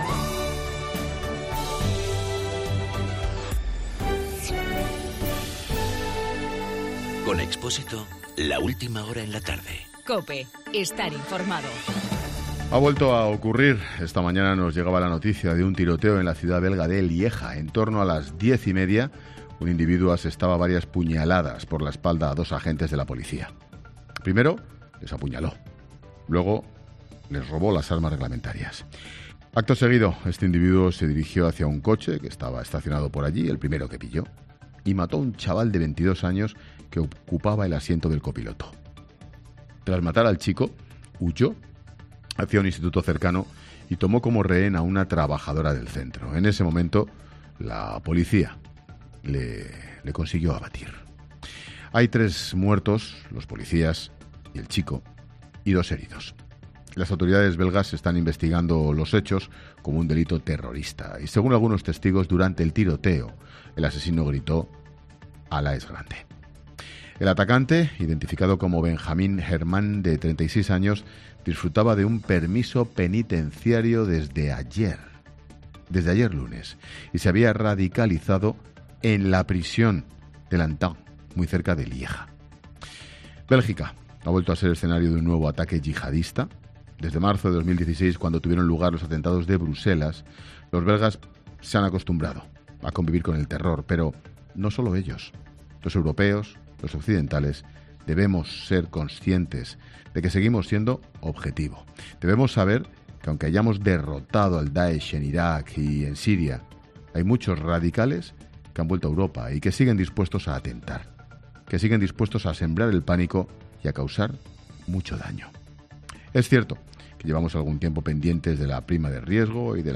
Monólogo de Expósito
El comentario de Ángel Expósito sobre el atentado en Lieja (Bélgica).